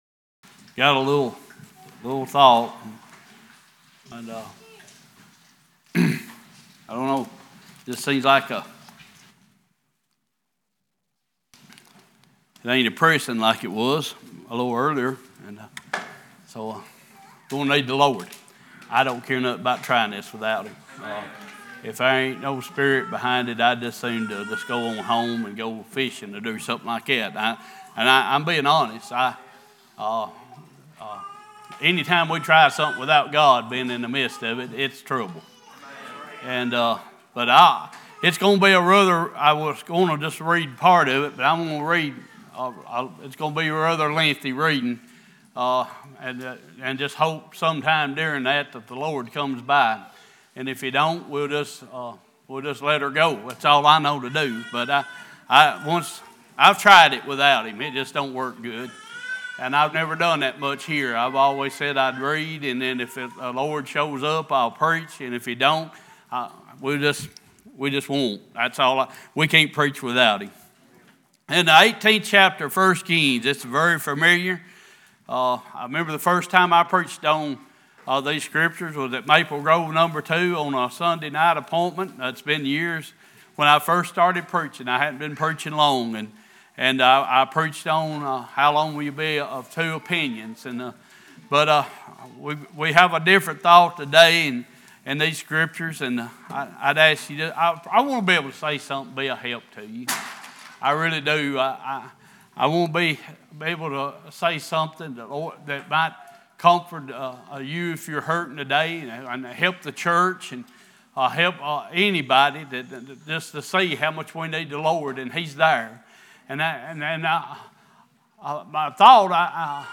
Isaiah 43:1-2 Service Type: Worship « Has The Word of God Been A Profit To You?